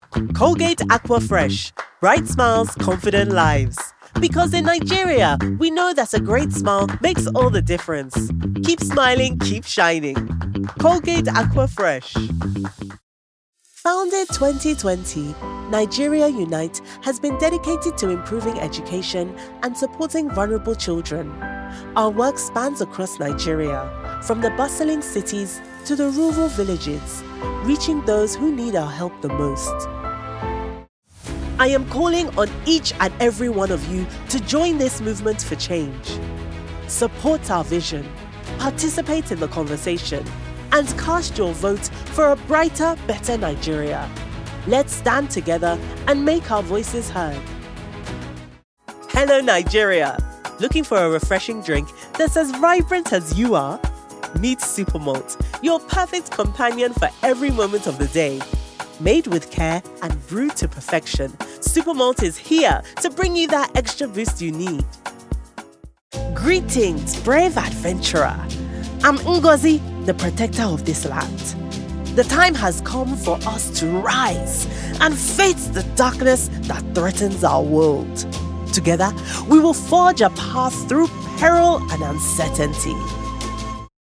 African Accent Showreel
Female
Nigerian